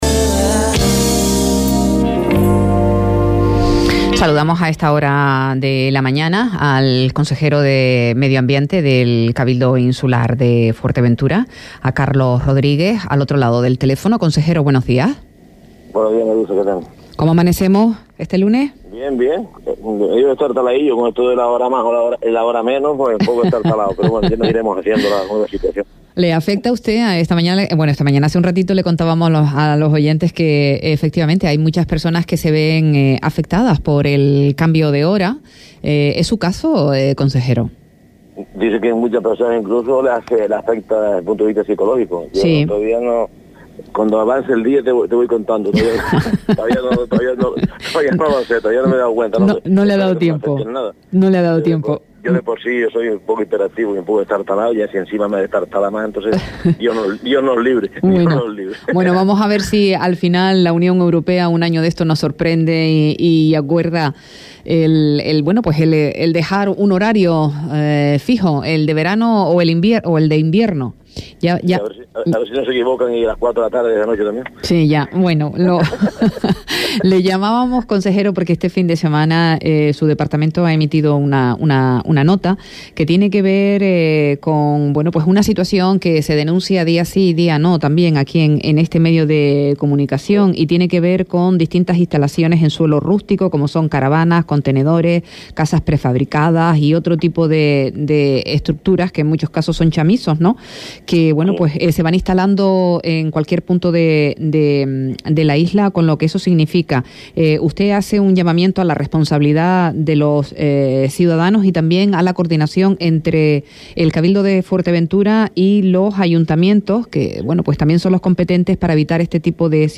A Primera hora, entrevista a Carlos Rodríguez, consejero de Medioambiente del Cabildo de Fuerteventura – 30.10.23
Entrevistas